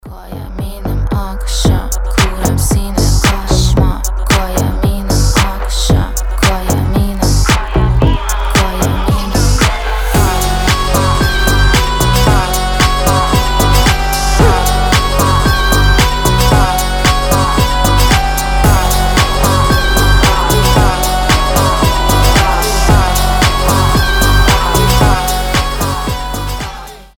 • Качество: 320, Stereo
женский голос
басы
качающие
татарские